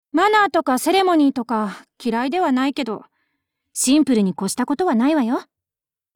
[[Category:碧蓝航线:德雷克语音]]